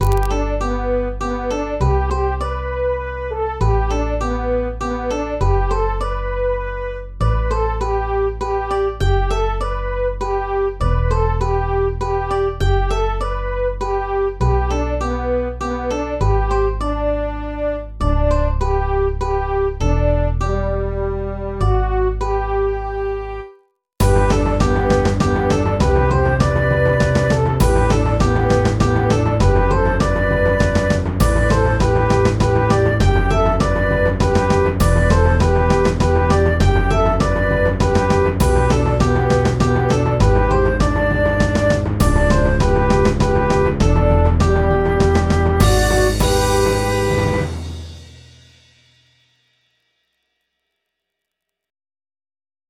MIDI 4.38 KB MP3 (Converted) 0.81 MB MIDI-XML Sheet Music